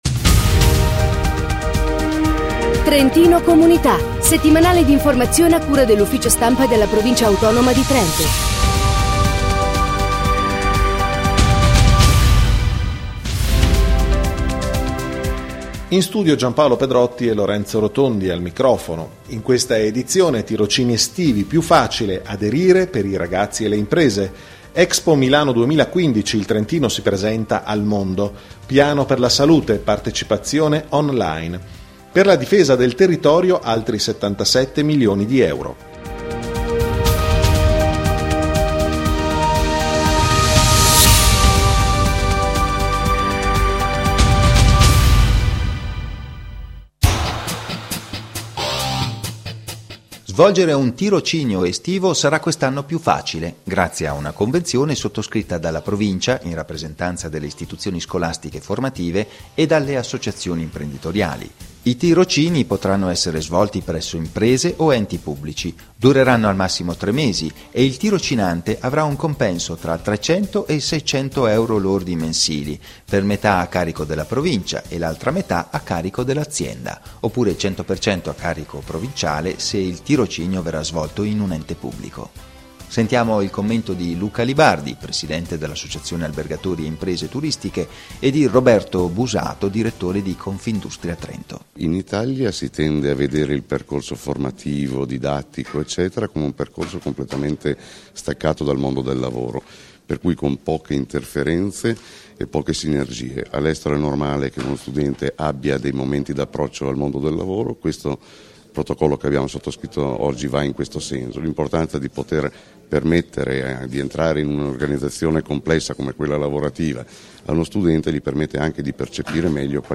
Notiziario Ambiente EXPO 2015 Famiglia, sociale e comunità